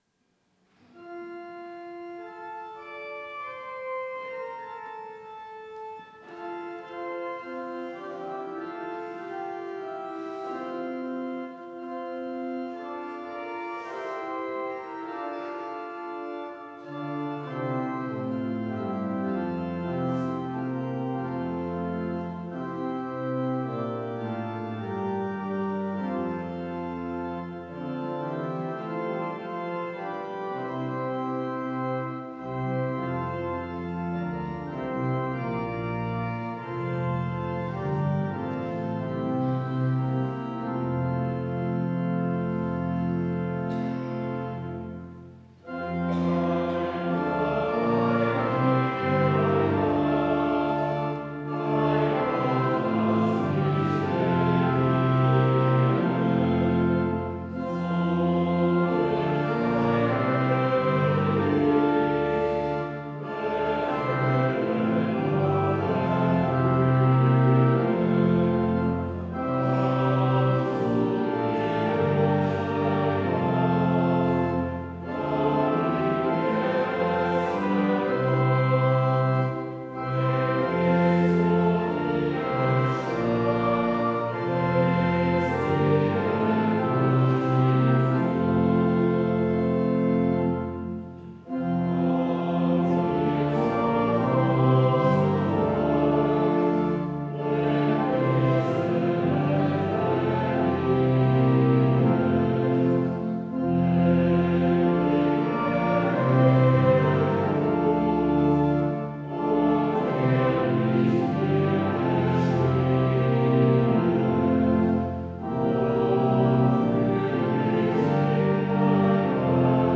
Audiomitschnitt unseres Gottesdienstes am Sonntag Invokavit 2025.